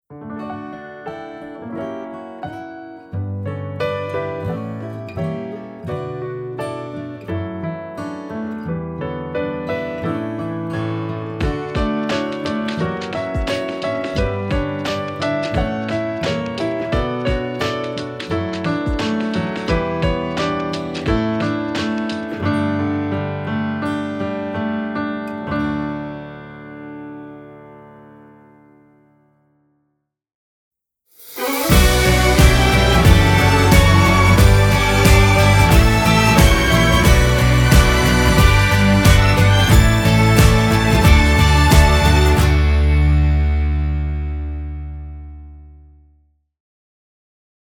INSTRUMENTAL ROCK / POPS
少し甘酸っぱさのある青春感にじむサウンドでまとめました。